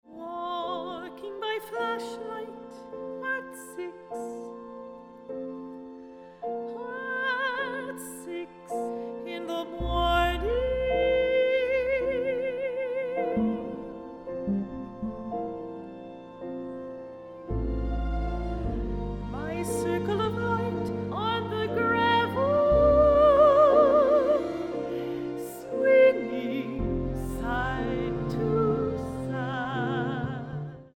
soprano
piano
clarinet